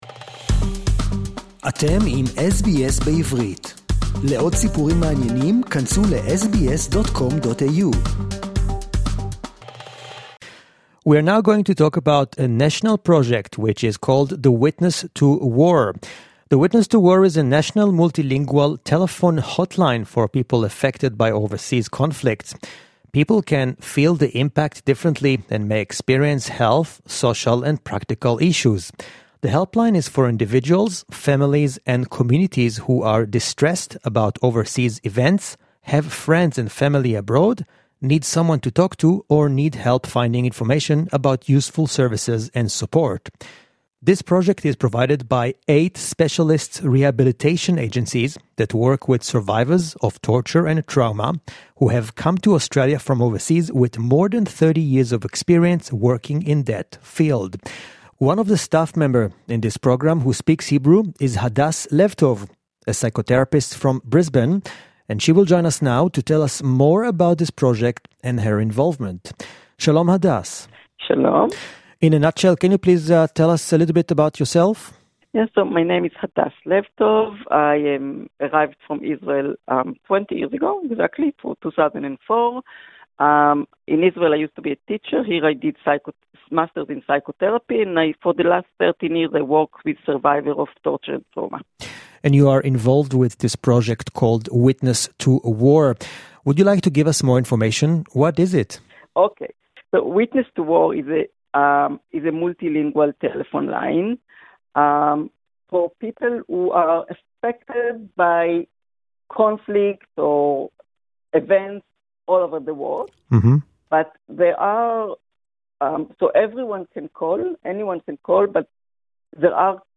(English interview)